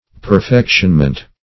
Search Result for " perfectionment" : The Collaborative International Dictionary of English v.0.48: Perfectionment \Per*fec"tion*ment\, n. [Cf. F. perfectionnement.] The act of bringing to perfection, or the state of having attained to perfection.